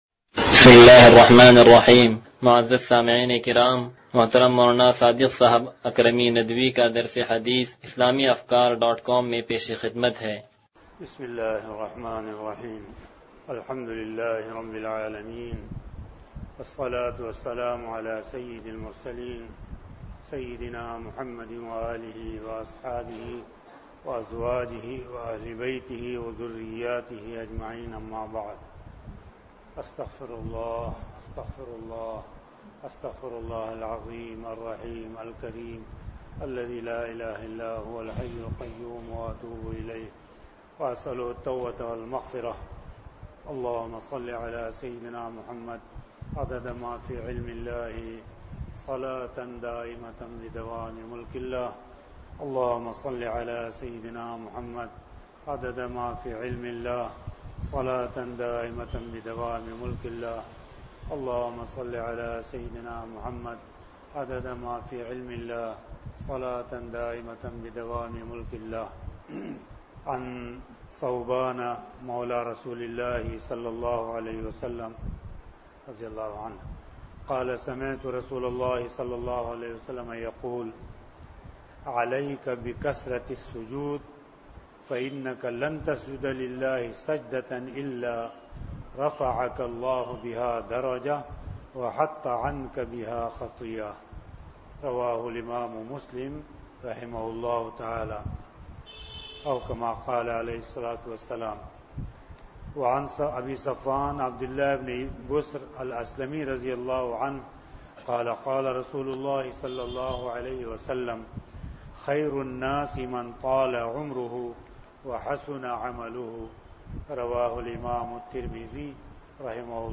درس حدیث نمبر 0116